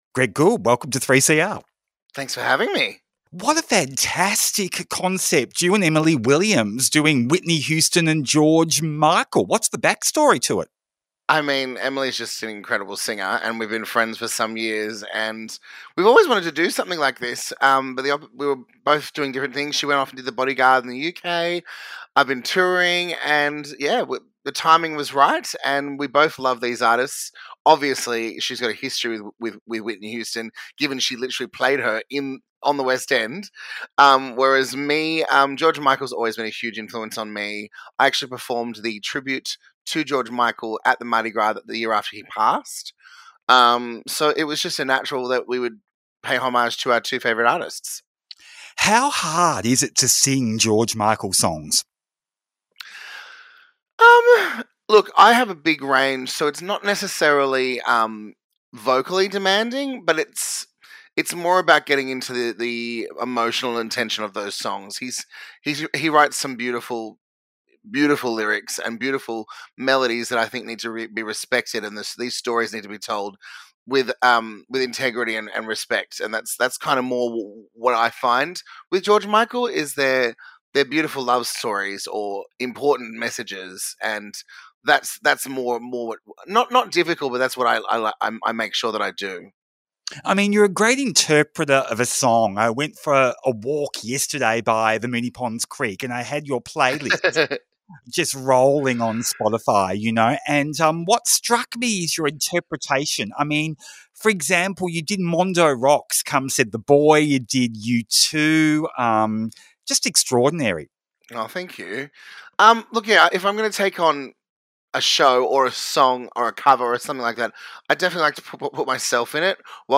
Official Site Home - The Brunswick Ballroom Tweet In Ya Face Friday 4:00pm to 5:00pm Explores LGBTIQA+ issues with interviews, music and commentary.